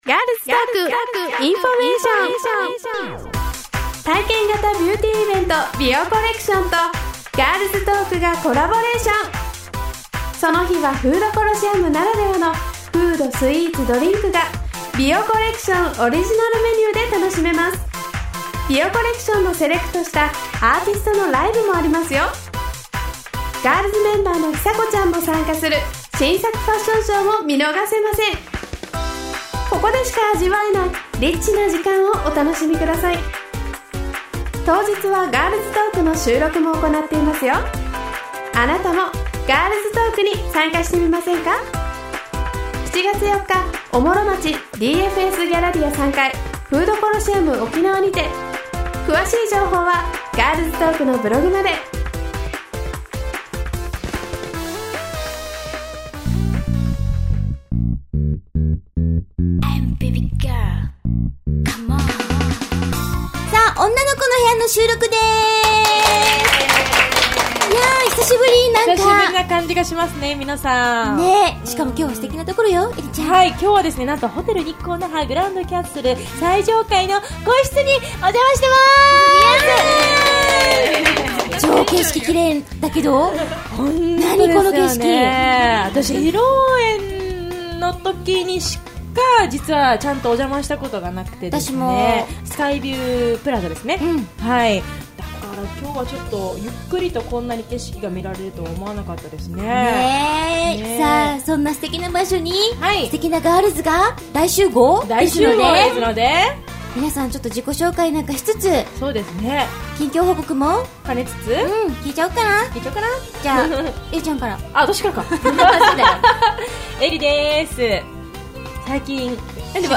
今回は「ホテル日航那覇 グランドキャッスル」の最上階で収録 ...